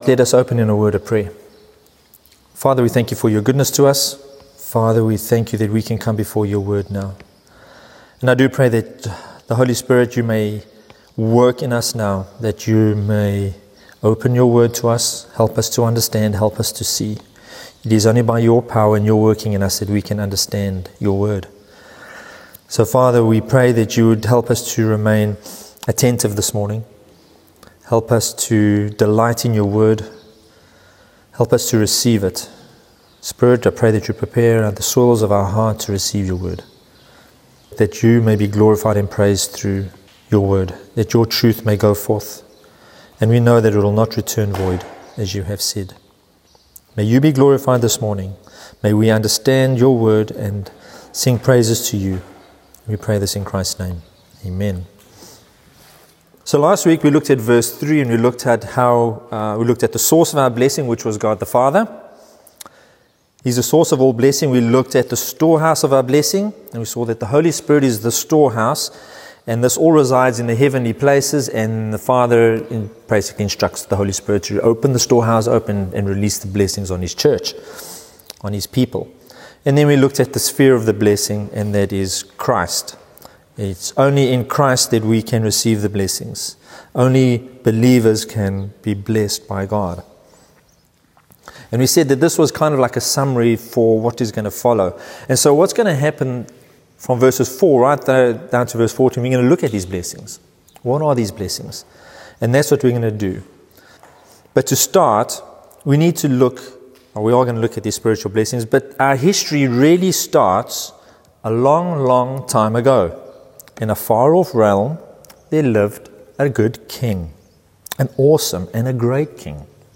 In this sermon we turn to Ephesians 1:4–6 and consider the breathtaking work of God the Father in salvation.